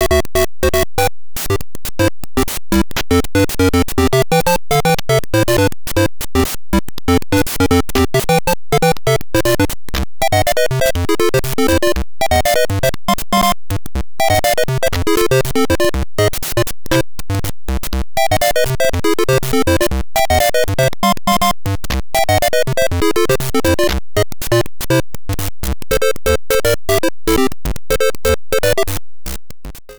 Self-captured from the Sharp X1 version